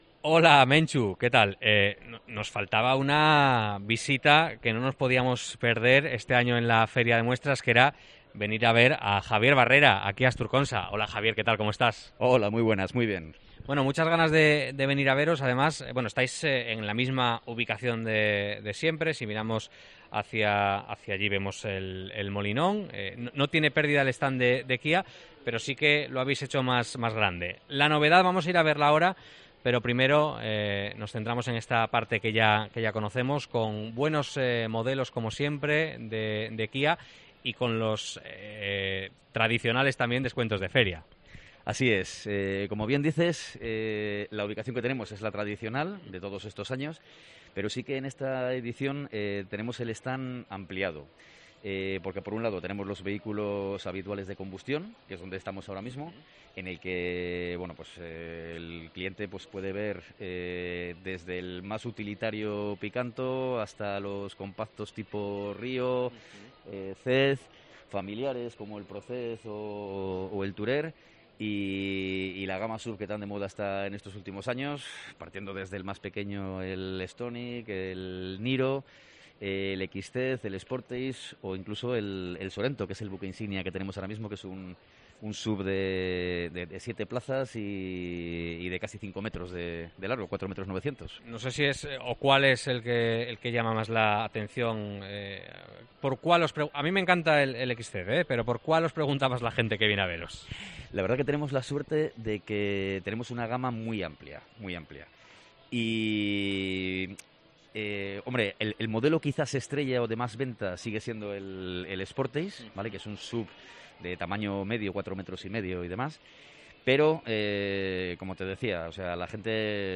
COPE EN LA FIDMA
Entrevista